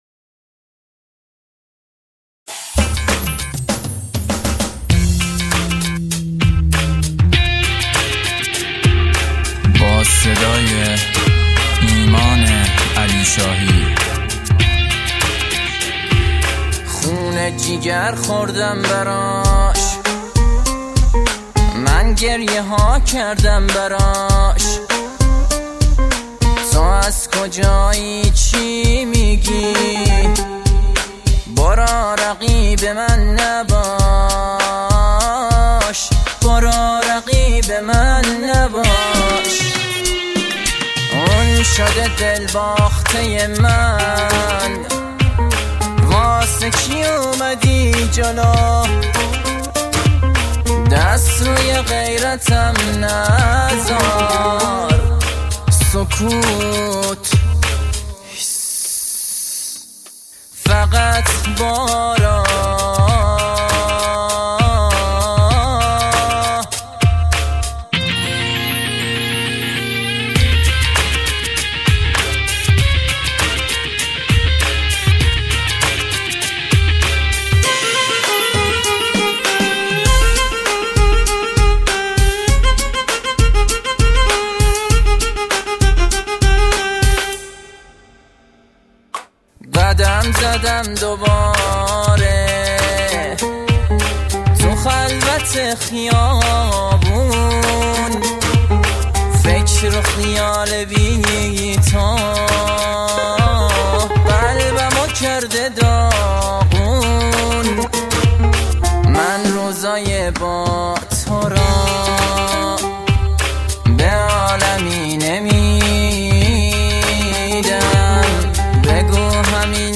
غمگین ، پاپ